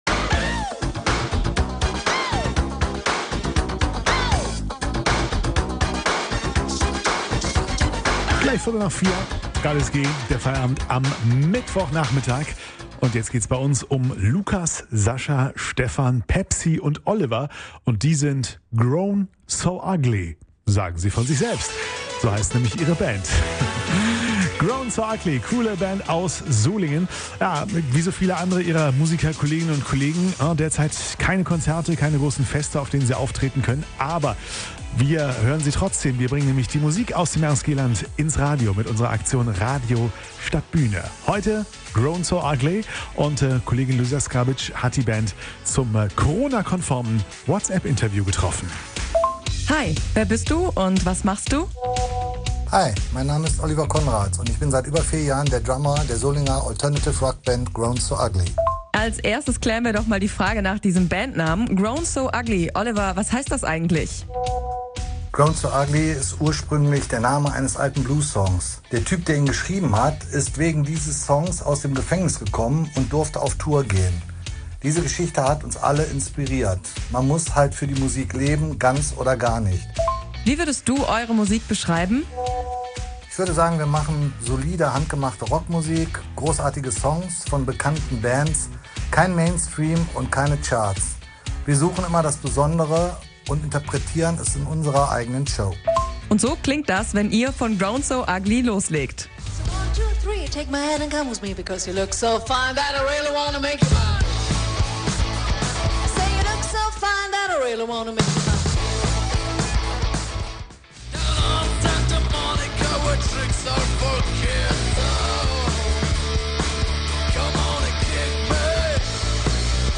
Rockband